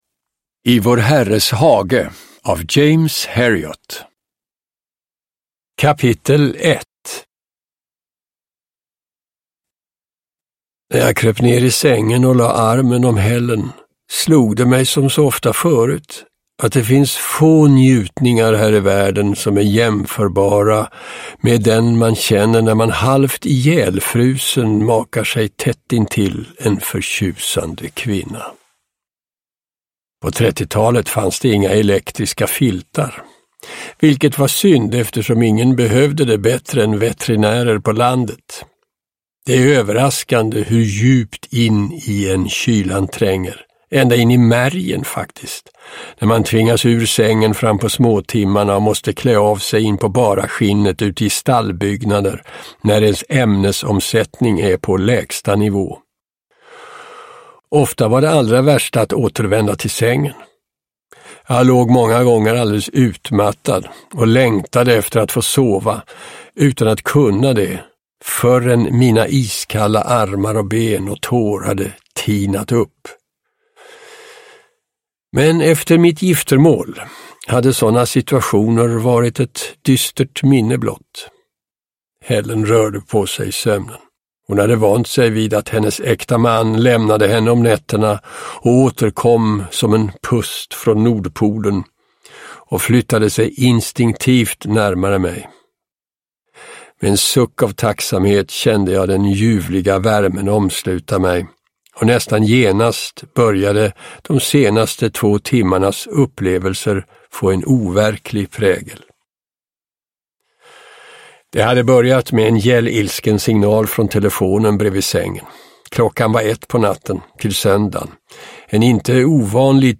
I vår Herres hage – Ljudbok – Laddas ner
Uppläsare: Björn Granath